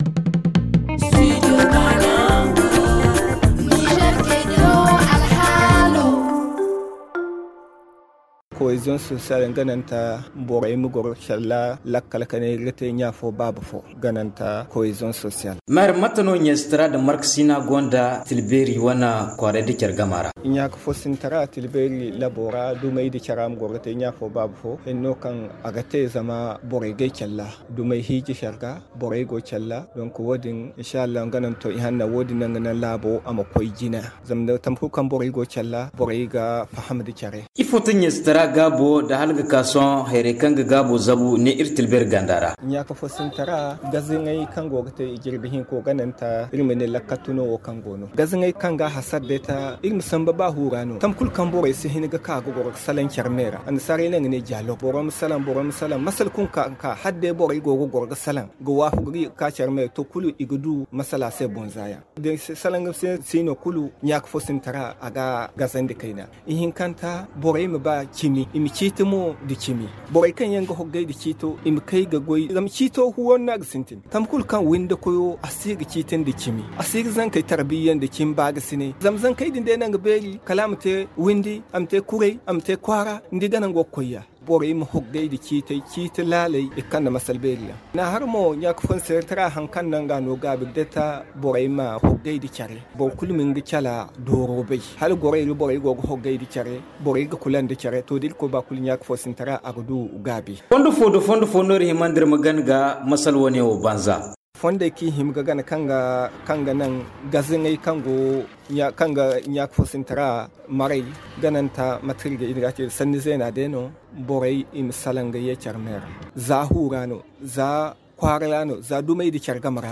Le magazine en zarma